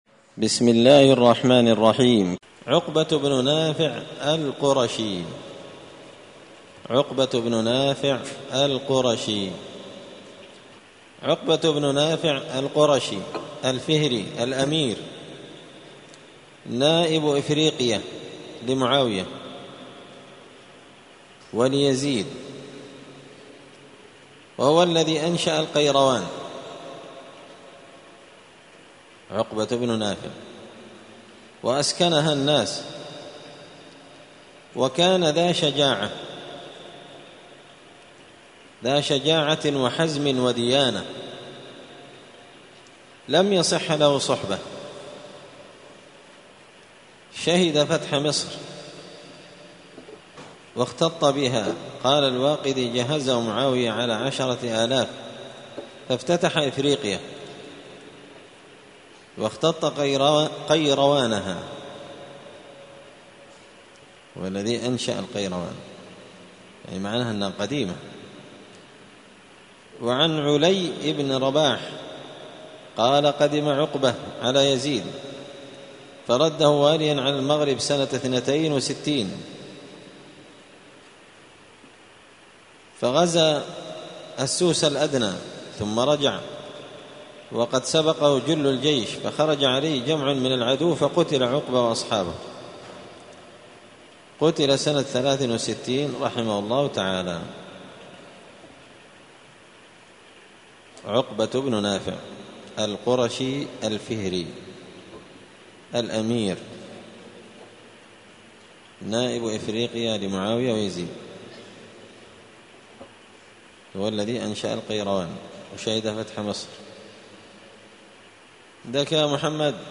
قراءة تراجم من تهذيب سير أعلام النبلاء